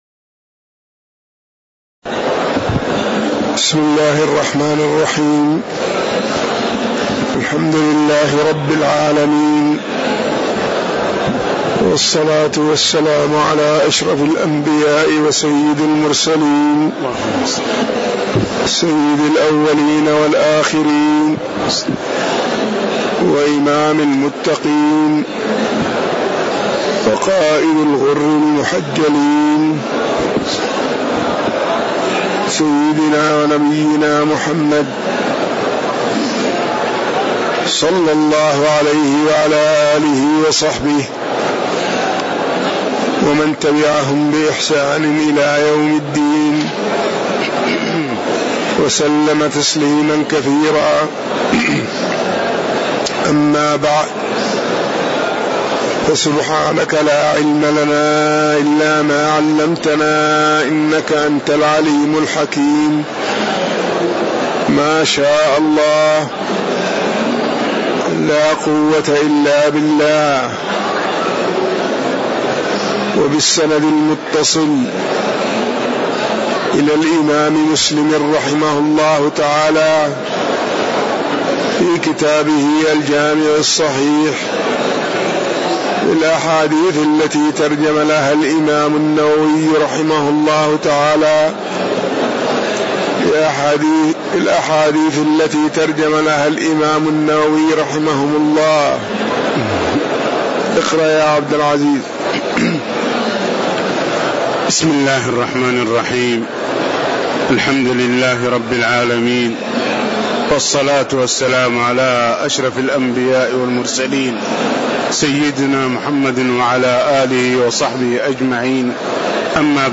تاريخ النشر ٢ جمادى الأولى ١٤٣٨ هـ المكان: المسجد النبوي الشيخ